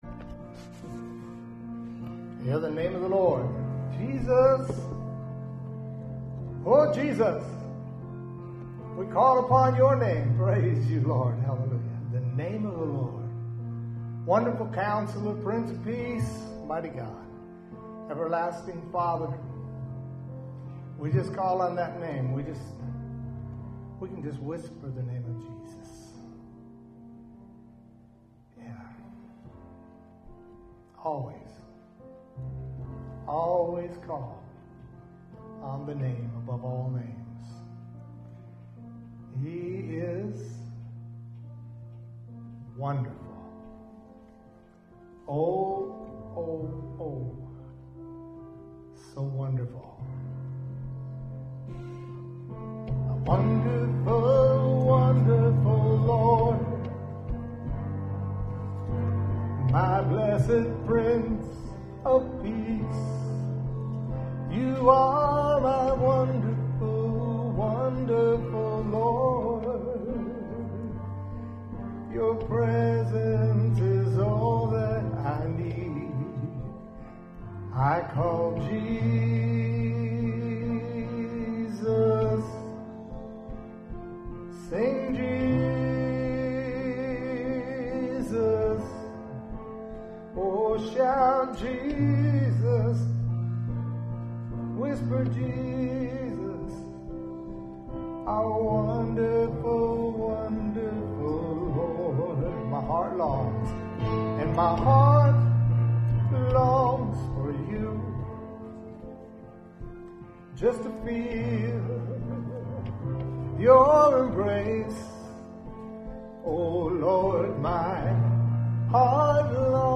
WORSHIP1126.mp3